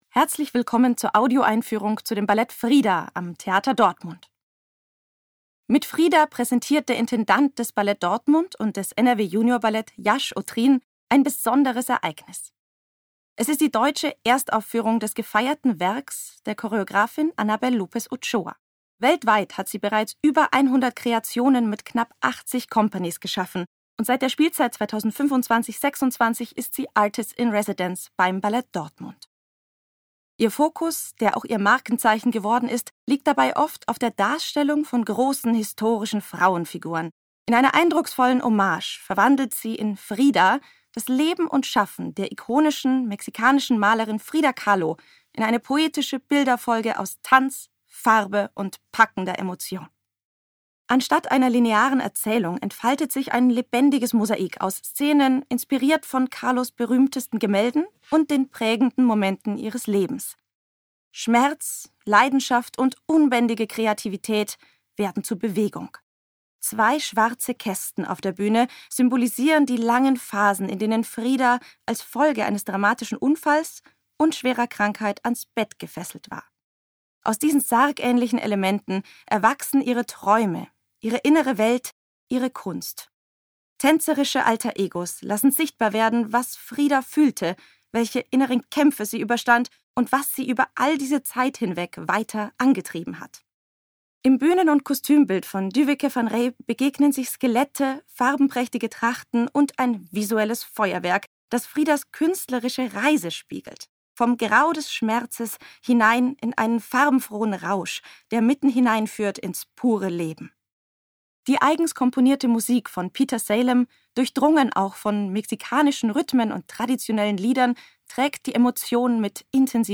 tdo_Audioeinfuehrung_Frida.mp3